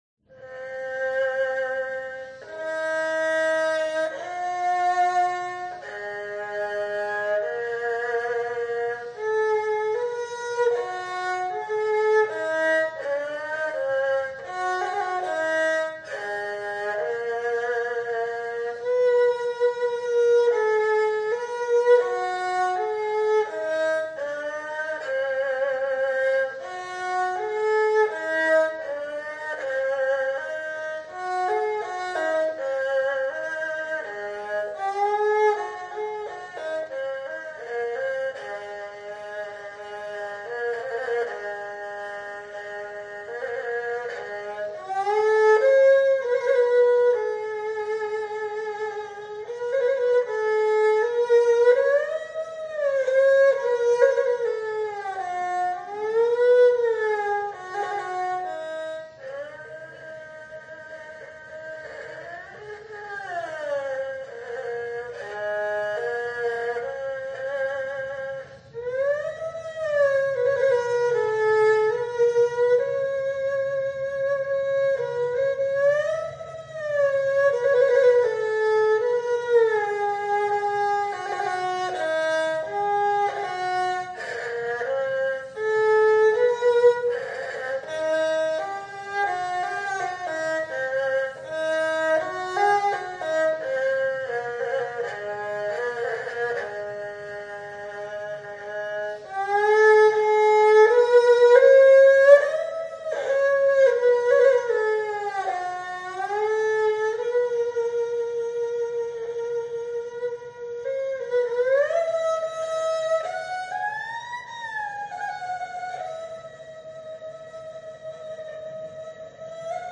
Good Nights (Liang Xiao) 良宵 by Liu Tianhua 劉天華, the “grandfather” of the modern erhu.
Composed in the 1920s, this piece relates the joyful occasion of the night before the Chinese New Year. It is played on the antique instrument displayed in the exhibition at Cambridge University Library, set up with silk strings and tuned to a lower pitch.